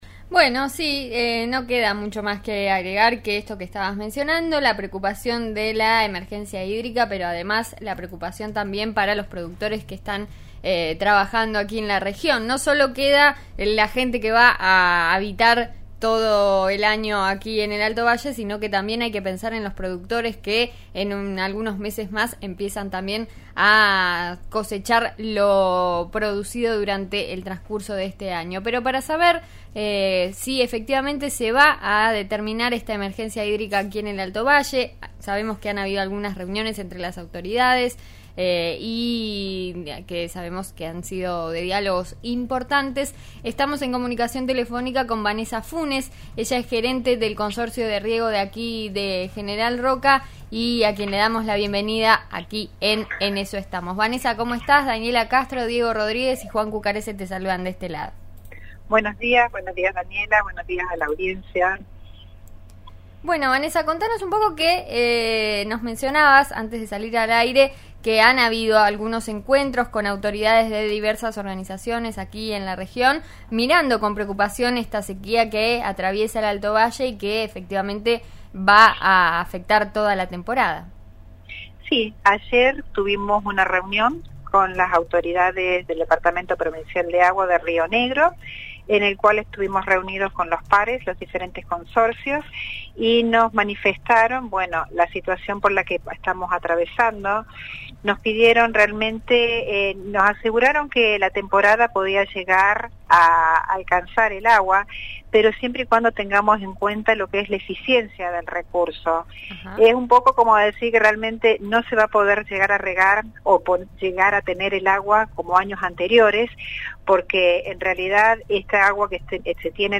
dialogó con 'En eso estamos' de RN Radio sobre la Emergencia Hídrica que tanto preocupa en la región.